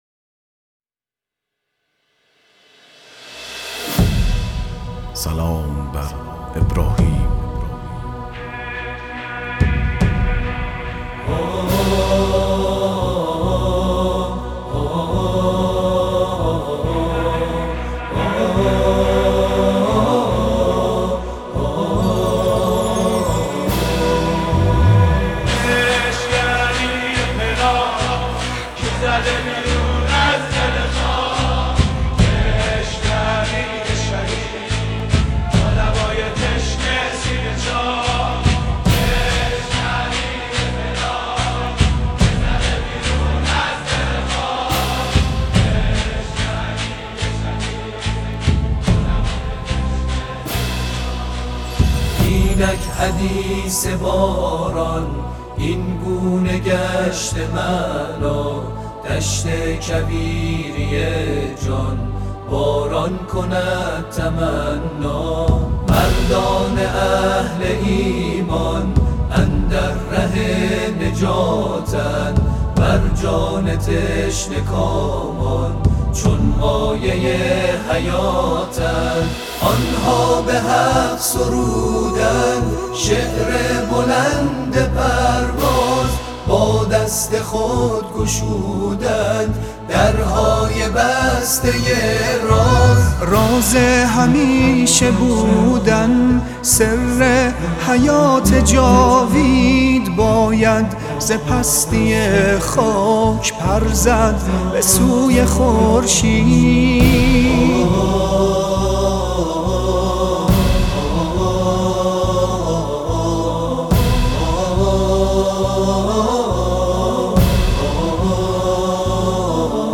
جمع خوانی گروه محراب
اجرا شده در یادواره شهید ابراهیم هادی